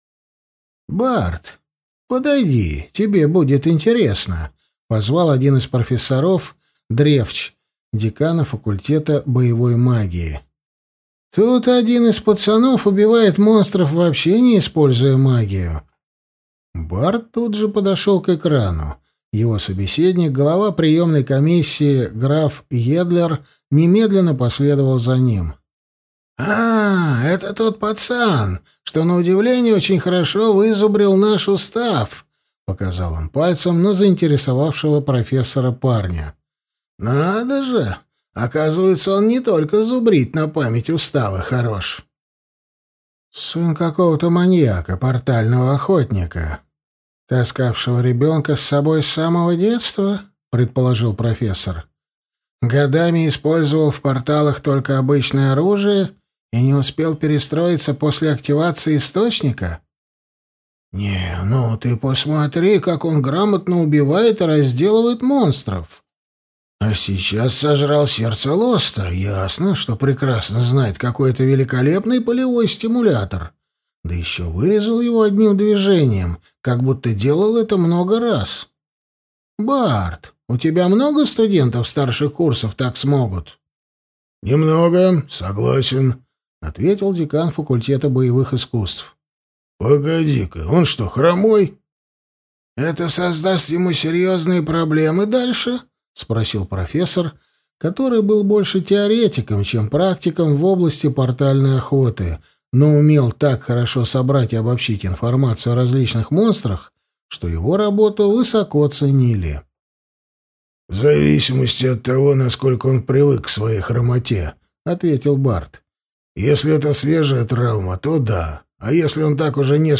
Аудиокнига Антидемон. Книга 2 | Библиотека аудиокниг